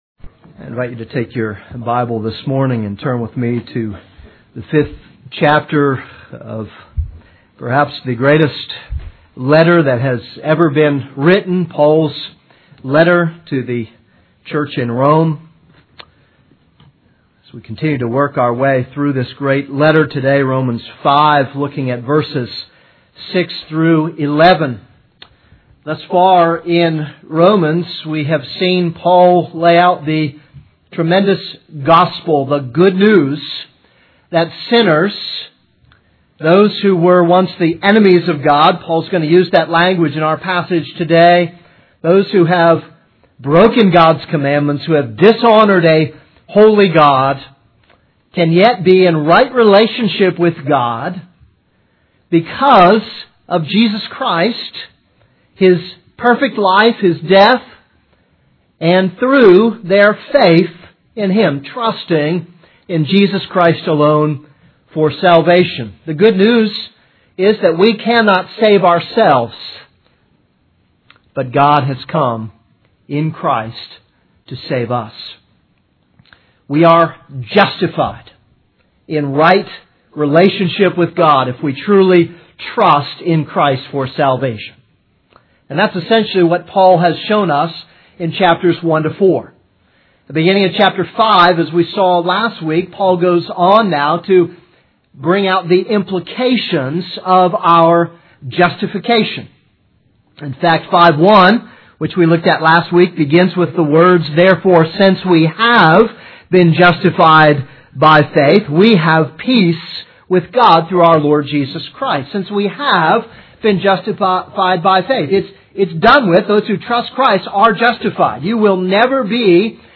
This is a sermon on Romans 5:6-11.